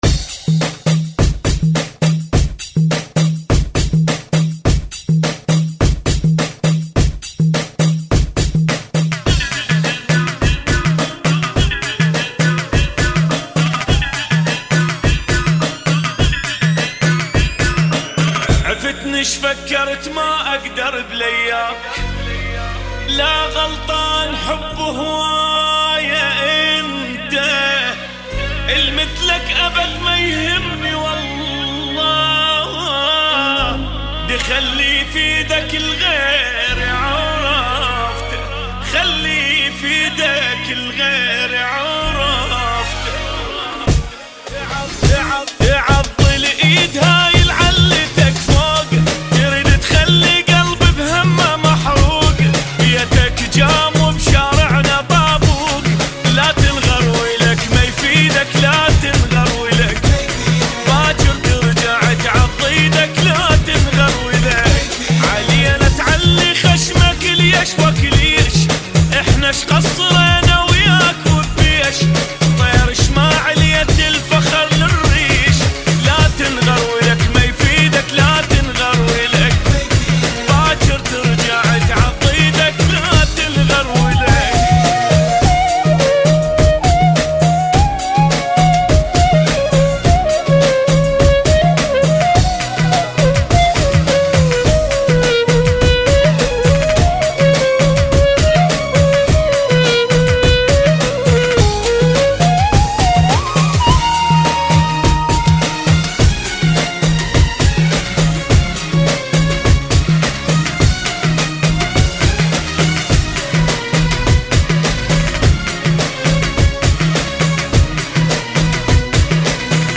Funky [ 104 Bpm ]